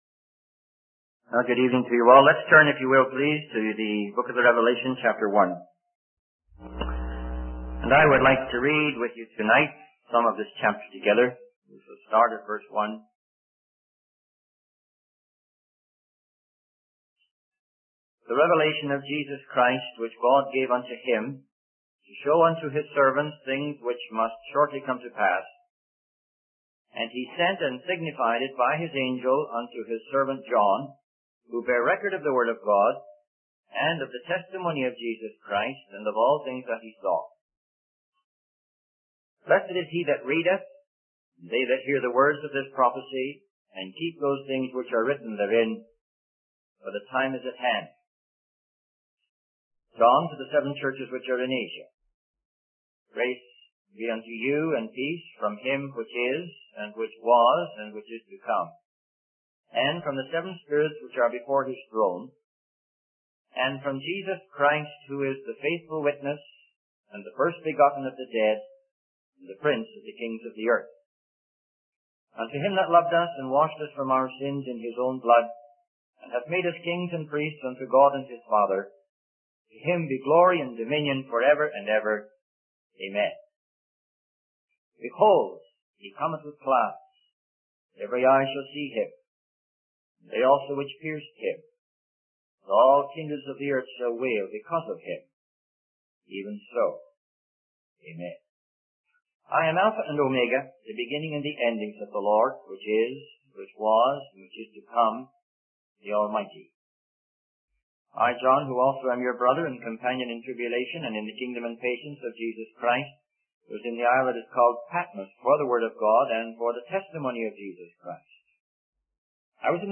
In this sermon, the speaker begins by reading from the book of Revelation chapter 1, emphasizing the importance of understanding the prophecy of Jesus Christ. He highlights the current state of the world, expressing disappointment in the injustices and unreliability of the justice system and promises of men. The speaker criticizes the idea that there are no absolutes, pointing out the contradiction in making an absolute statement about the absence of absolutes.